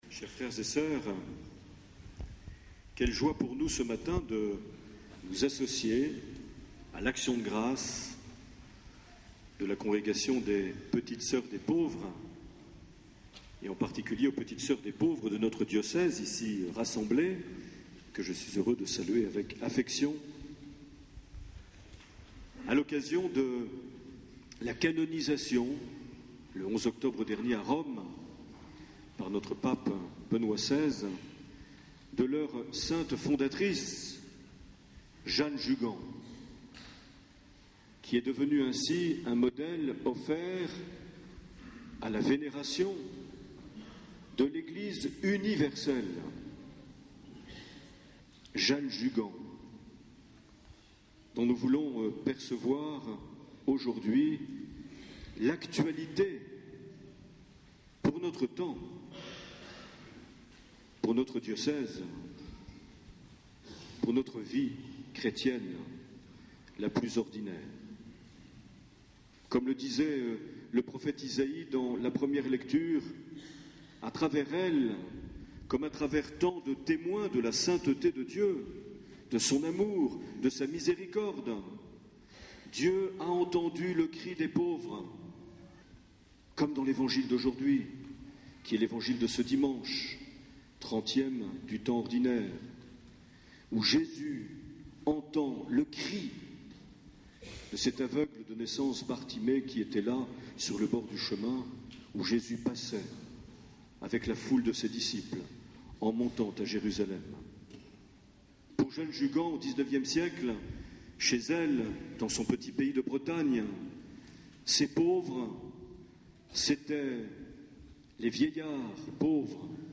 25 octobre 2009 - Pau église Saint Joseph - Messe en l’honneur de Sainte
Accueil \ Emissions \ Vie de l’Eglise \ Evêque \ Les Homélies \ 25 octobre 2009 - Pau église Saint Joseph - Messe en l’honneur de (...)
Une émission présentée par Monseigneur Marc Aillet